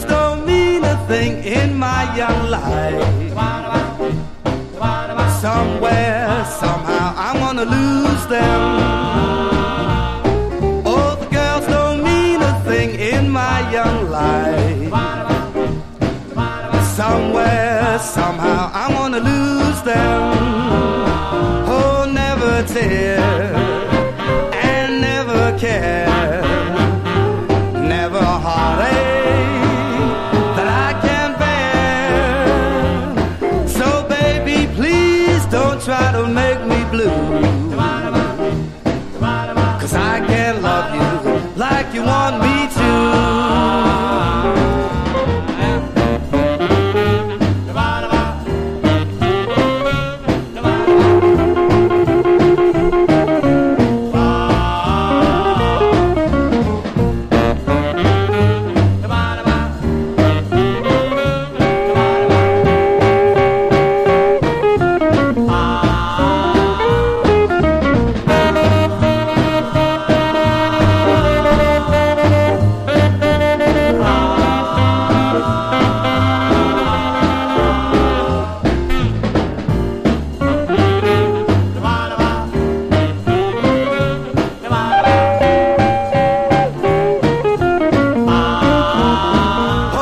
スウィング～ジャイヴな演奏に乗せてオーソドックスでキュートなコーラスが飛び交う軽快な聴き心地。
VOCAL JAZZ